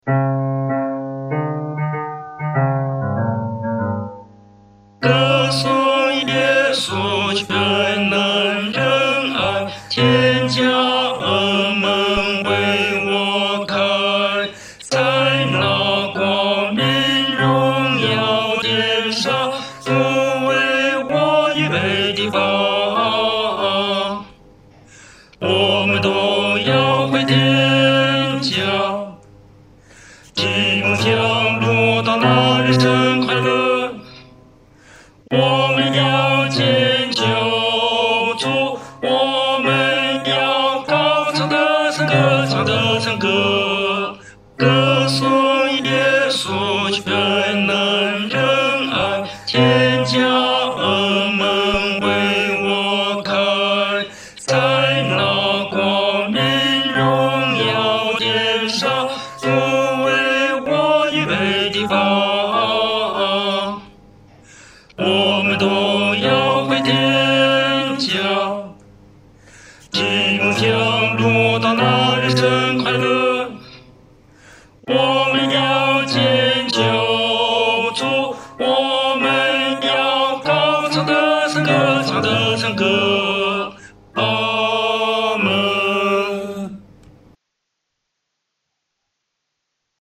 男高